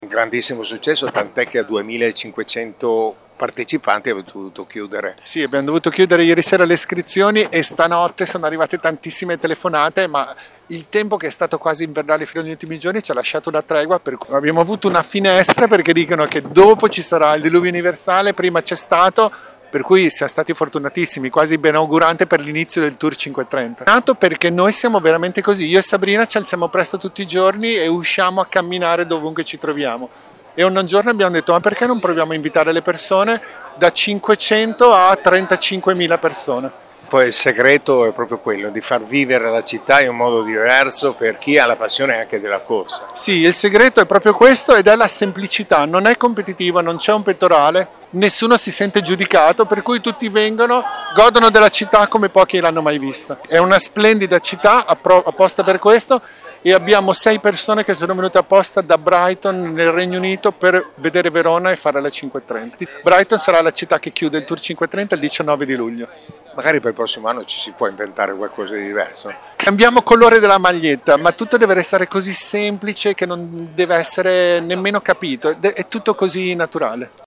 Presente alla corsa anche Radio Pico, media partner dell’evento.